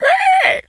pig_scream_short_01.wav